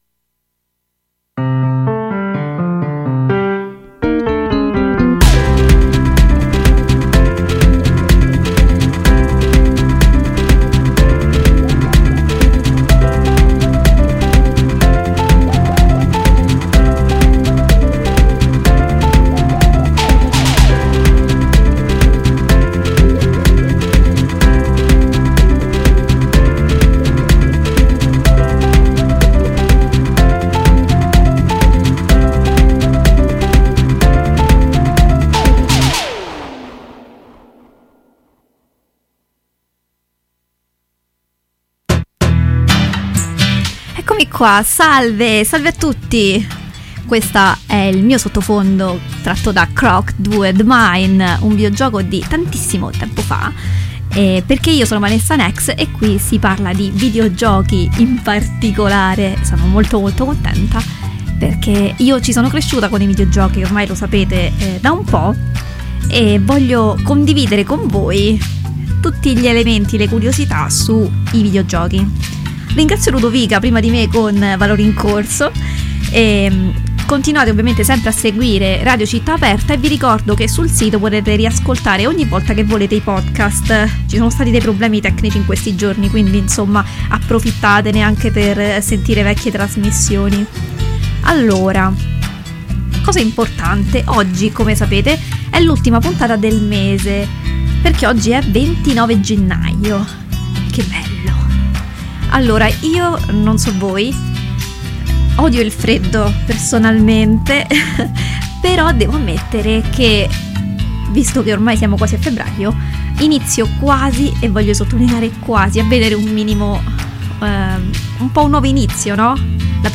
In questo episodio presentiamo la nuova uscita del mese di Gennaio, The Medium. Intervista esclusiva